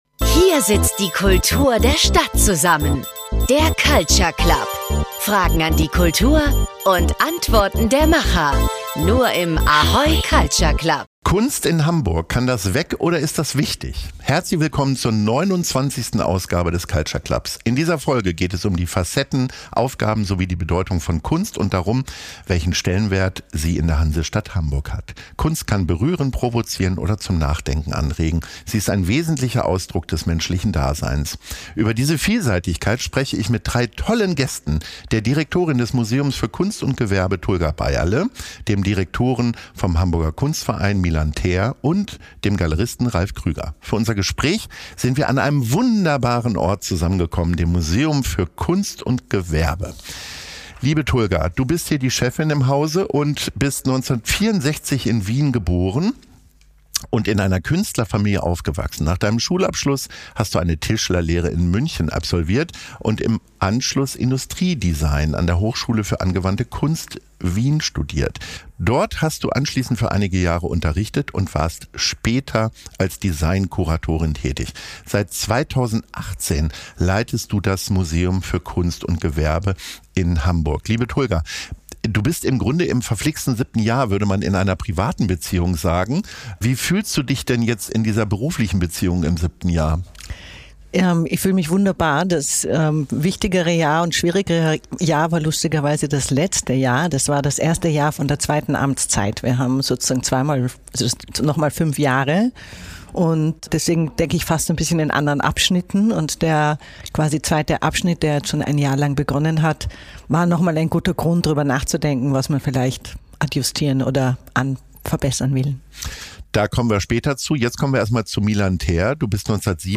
Das Ganze im gewohnt spaßigen Schnack. Ganz viel Glitzer, Glanz und „horsing around“, dynamisch verpackt in einer Stunde.